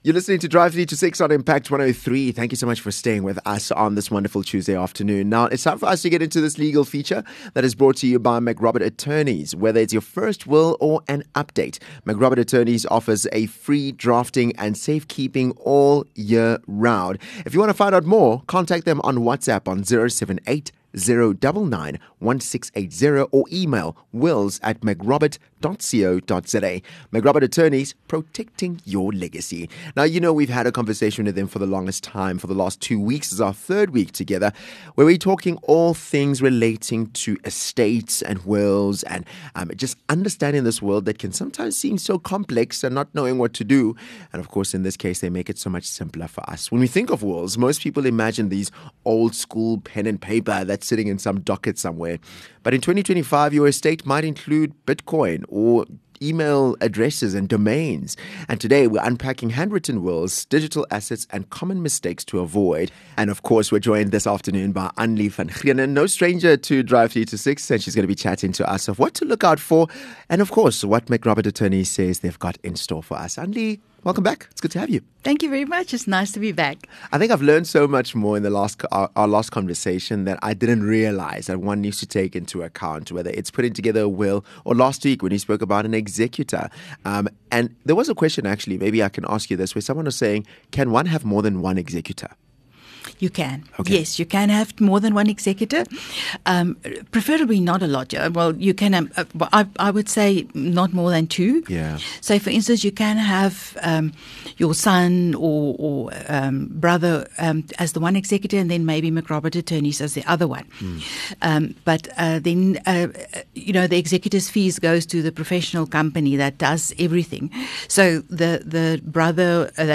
Interview 3